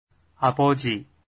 発音と読み方
아버지 [アボジ]